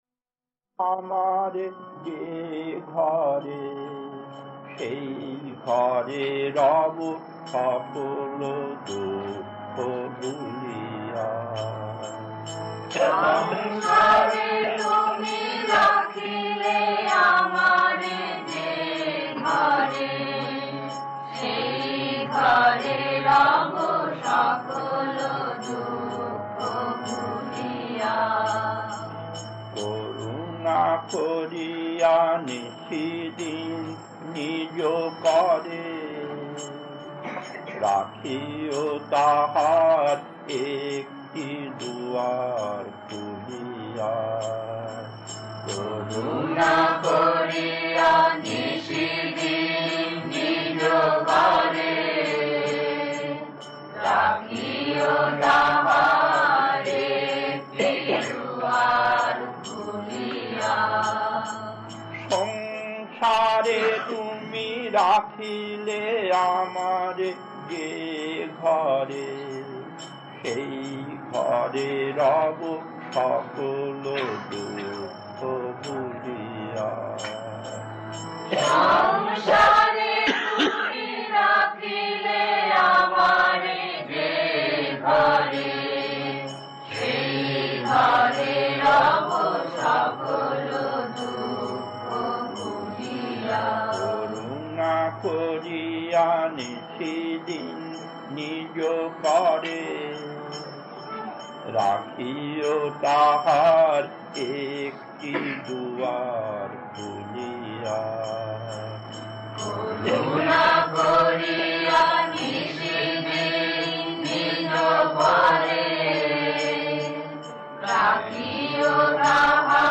Kirtan B6-2 Rockdale Bombay late 70�s, 38 minutes 1.